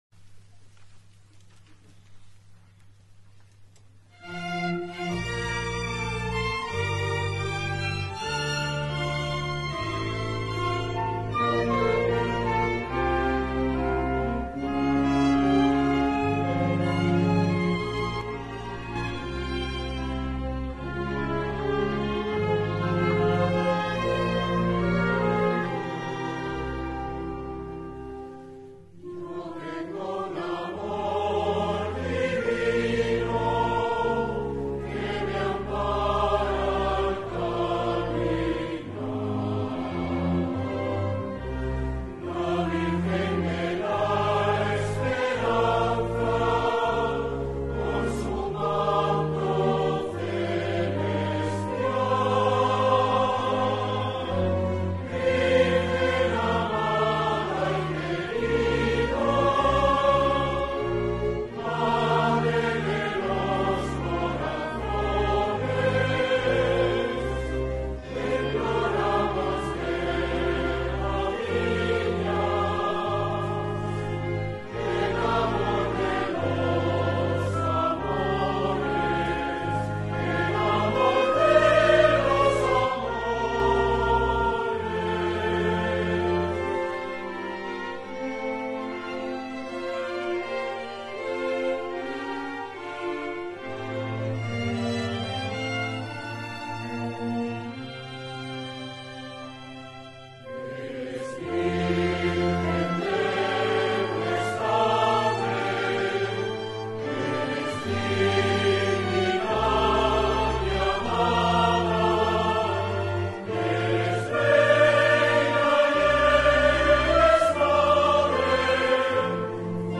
Himno Stma. Virgen de la Esperanza